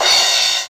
Index of /90_sSampleCDs/Sound & Vision - Gigapack I CD 1 (Roland)/CYM_CRASH mono/CYM_Crash mono
CYM CRA03.wav